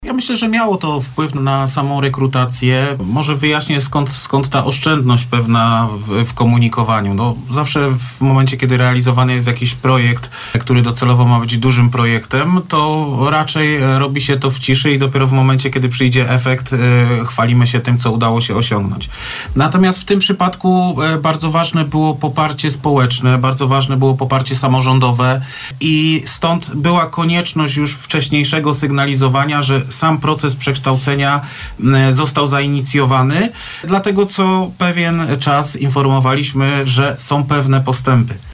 W trakcie rozmowy na naszej antenie pytaliśmy: na ile komunikowanie o tym, że ATH zmierza w stronę przekształcenia w uniwersytet – wpłynęło na rekrutację?